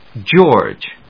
/dʒˈɔɚdʒ(米国英語), dʒˈɔːdʒ(英国英語)/